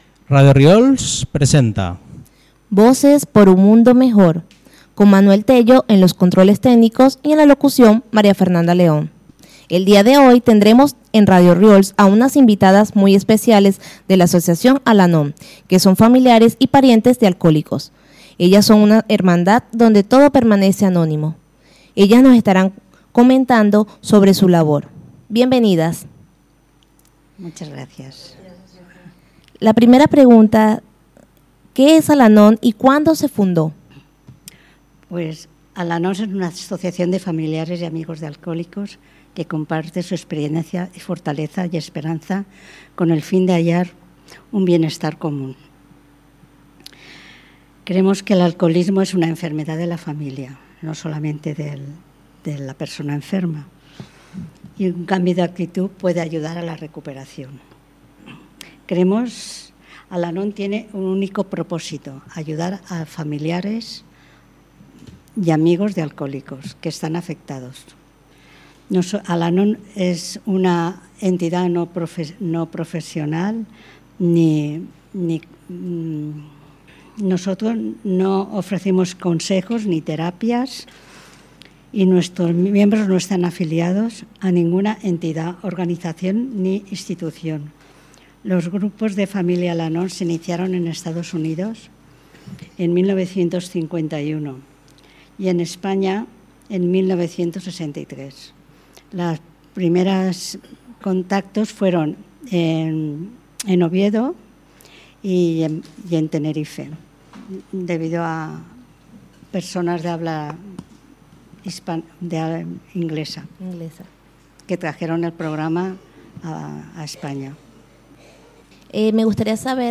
Entrevista a Al-Anon – Radiorriols
Entrevista-Alanon.mp3